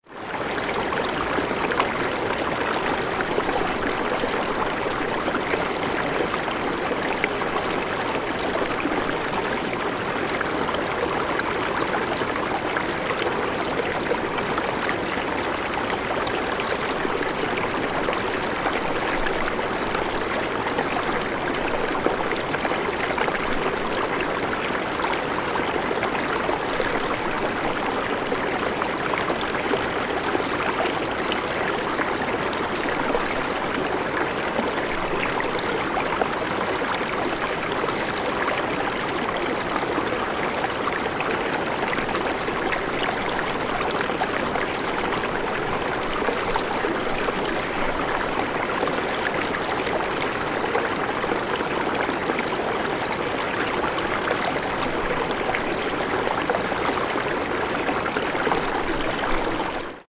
На этой странице собраны натуральные звуки рек и ручьев в высоком качестве.
Река течет бежит журчит вода